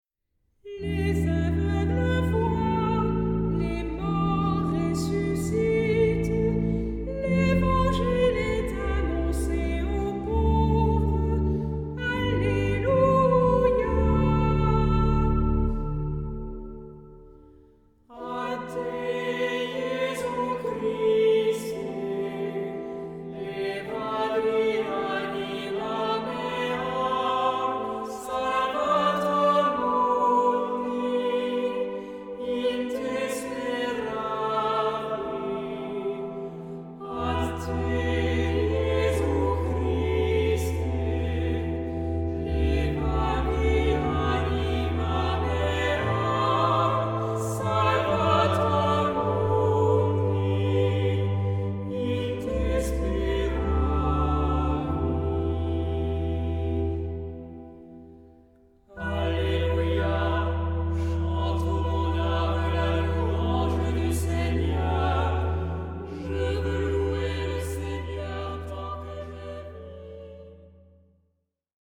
SAH O SATB (4 voces Coro mixto) ; Partitura general.
Salmodia.